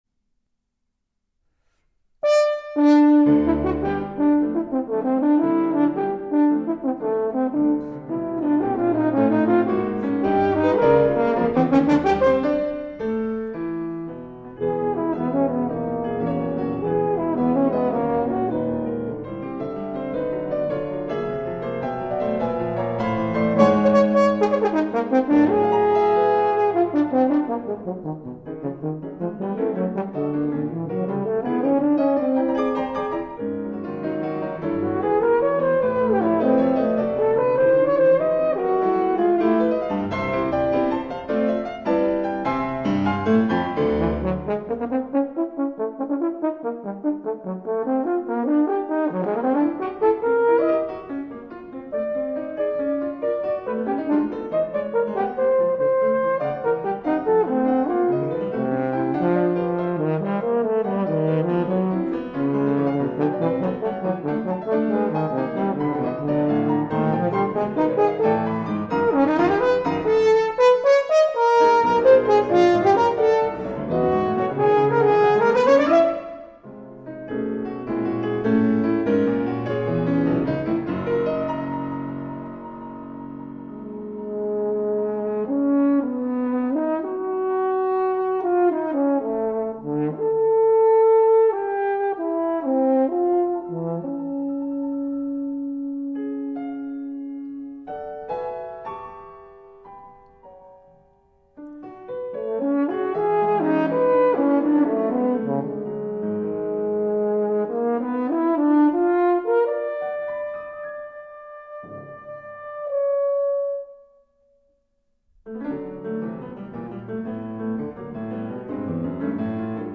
ein Projekt der Musikhochschule Lübeck
Klavier
für Horn und Klavier